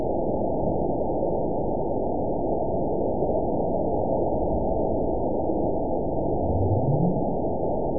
event 920426 date 03/24/24 time 21:58:52 GMT (1 year, 1 month ago) score 9.65 location TSS-AB02 detected by nrw target species NRW annotations +NRW Spectrogram: Frequency (kHz) vs. Time (s) audio not available .wav